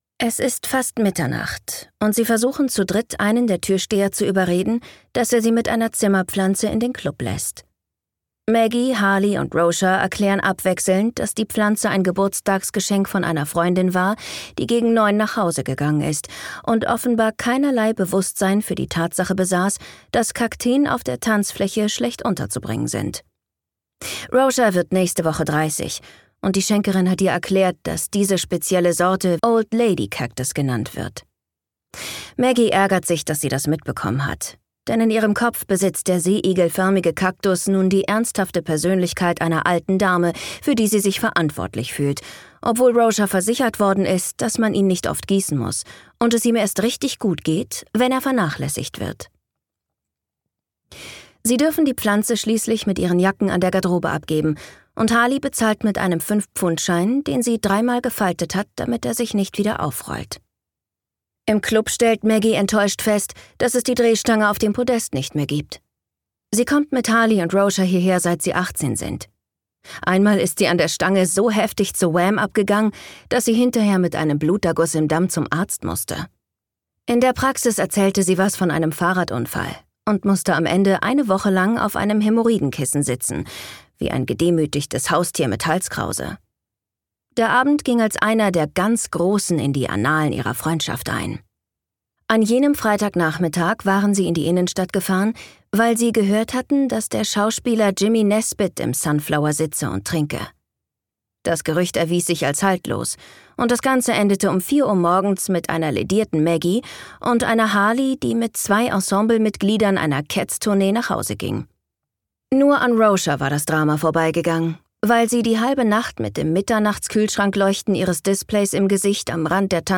Weird Girls - Gráinne O’Hare | argon hörbuch
Gekürzt Autorisierte, d.h. von Autor:innen und / oder Verlagen freigegebene, bearbeitete Fassung.